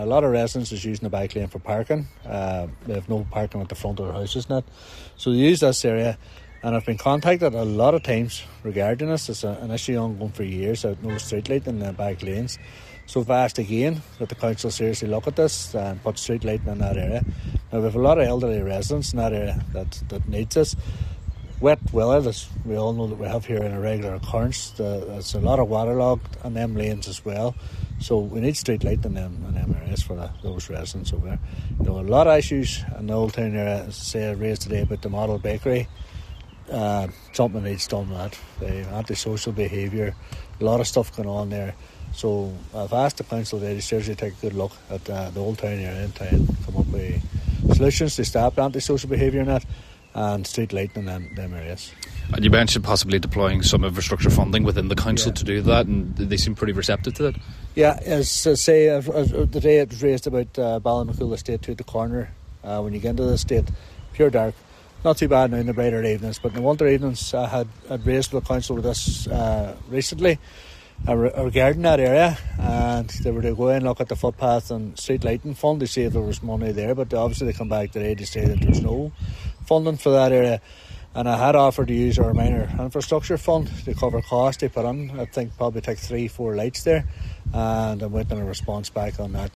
Cllr Tomás Seán Devine tabled the motion, he says he is waiting on a response: